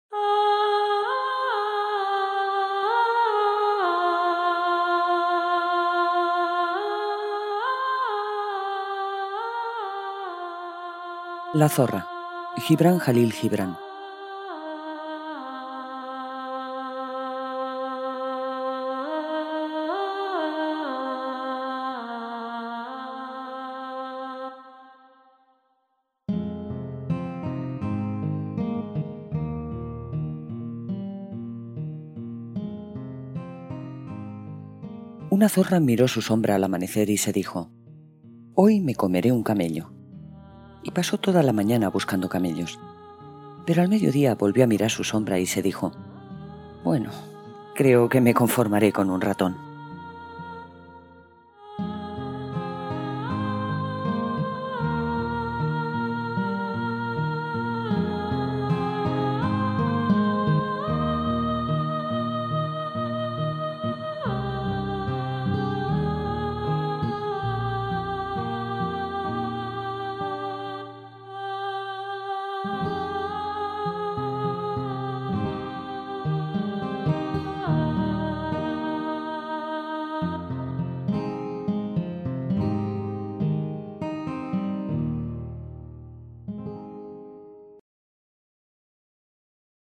Audiolibro: La zorra